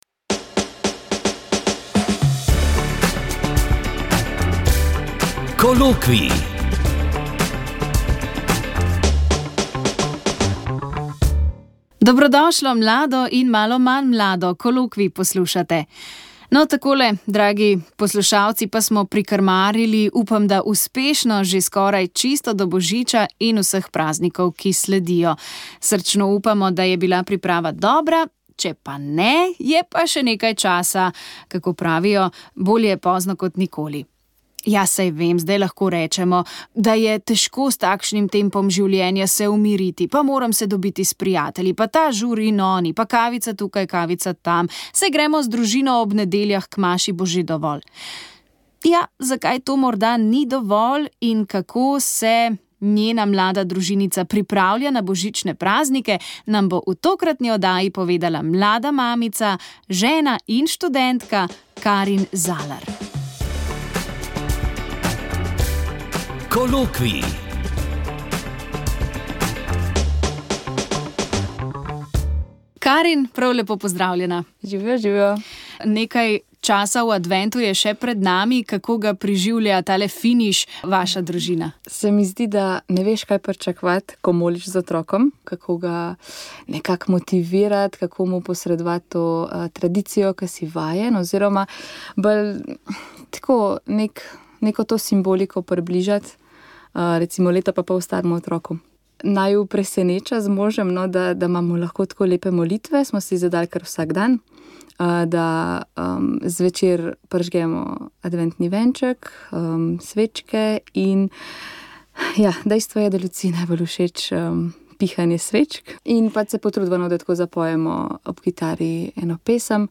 Molile so redovnice - Karmeličanke.